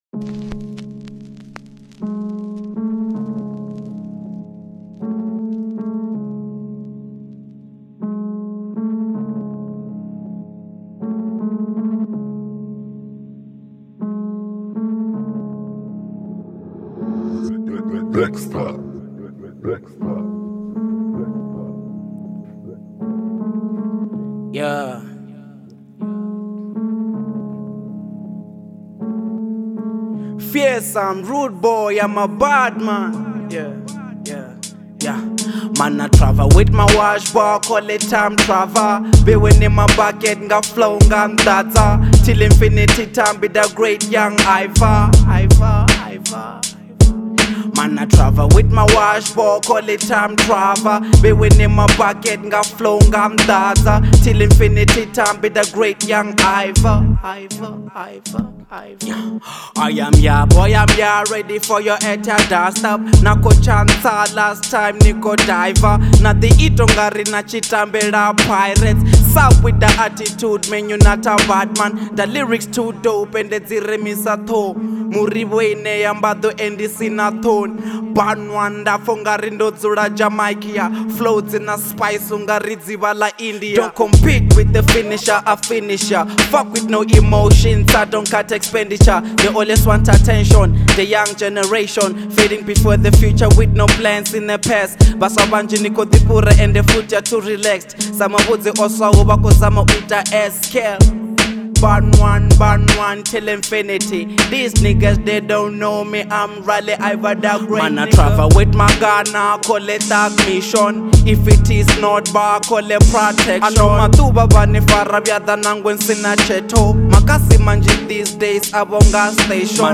02:36 Genre : Venrap Size